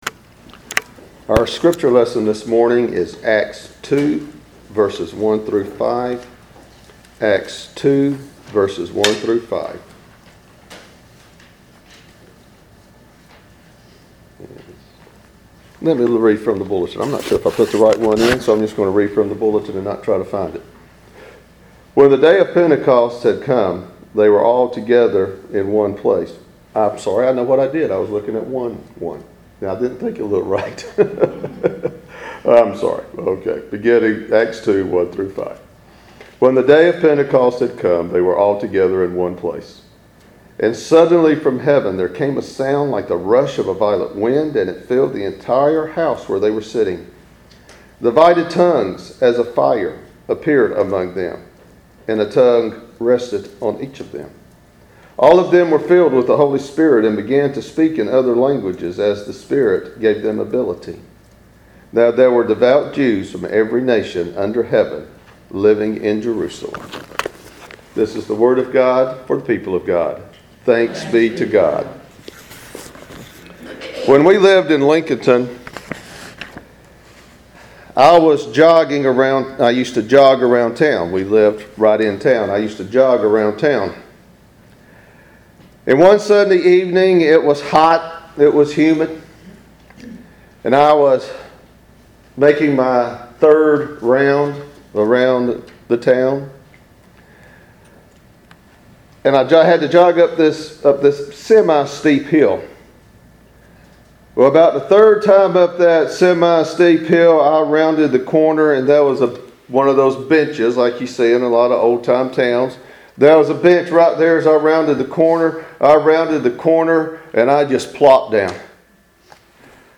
Walker Church SERMONS